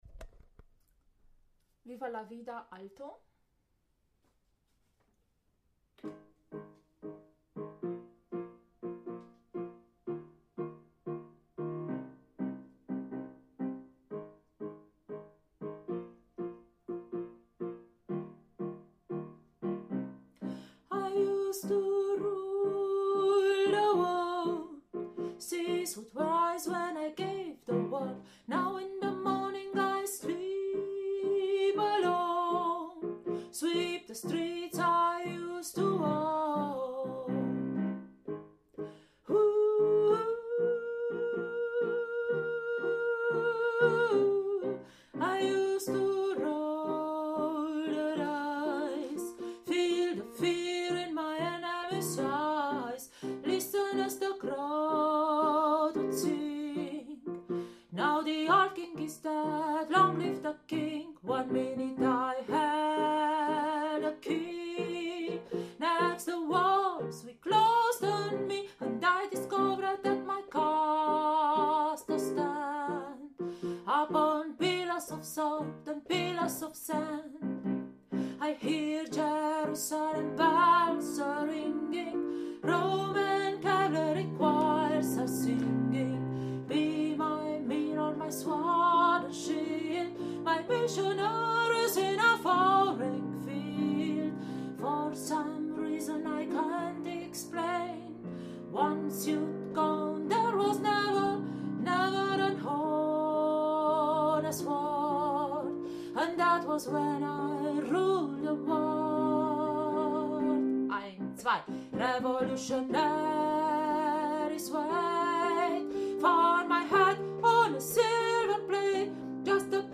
Viva la Vida – Alto